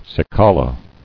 [ci·ca·la]